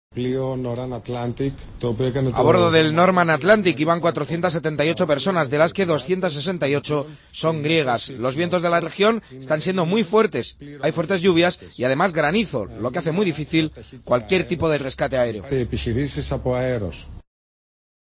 Escucha al ministro de la Marina Mercante de Grecia hablando del rescate
Entrevistas